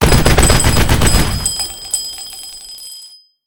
machine2.ogg